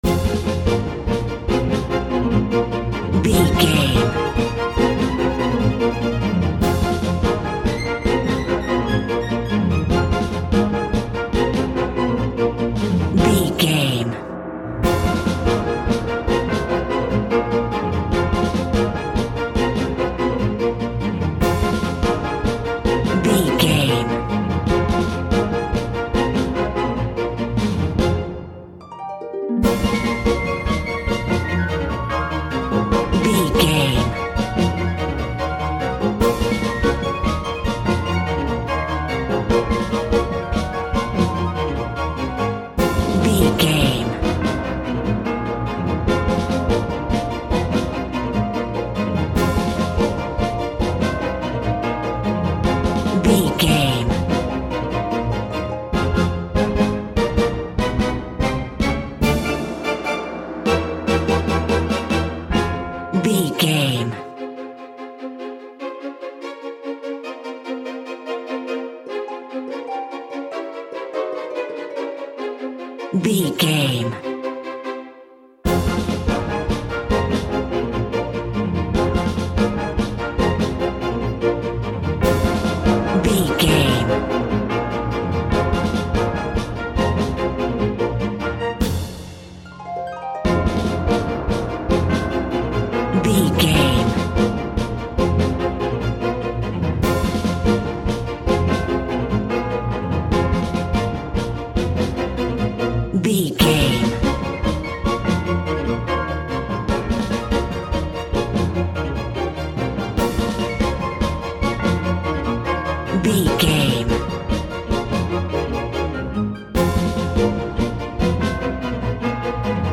Ionian/Major
Fast
frantic
orchestra
violin
strings
brass section
bells
cello
piccolo
flute
tense
driving
aggressive
dramatic
drums
harp
horns
percussion